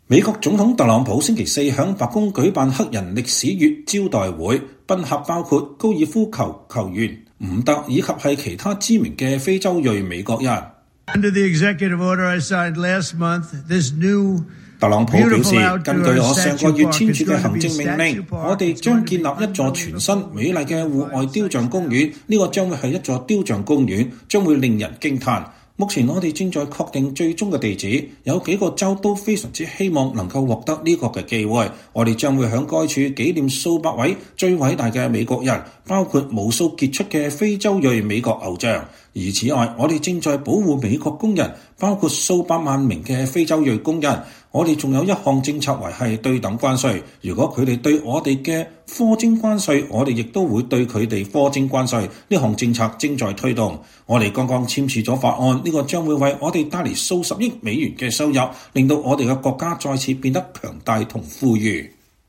美國總統特朗普星期四在白宮舉辦黑人歷史月招待會，賓客包括高爾夫球球員泰格伍德以及其他知名的非裔美國人。